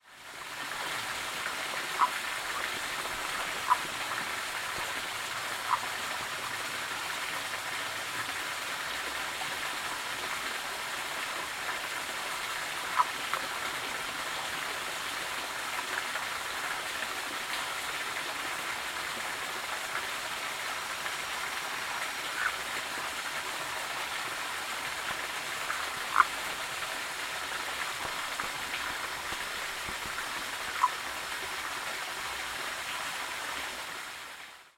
San Jose, Costa Rica
Turns out they did call that night even though it hadn't rained that day.
He describes it as a call havig 17 pulses per second, whereas my call is a single percussive note, albeit a course one?
The call that I recorded is best described as a quiet "croak" or "puck" sound.
Here's another recording made of a different individual where the calls are a bit more frequent.
Blue-sided Leaf Frog calls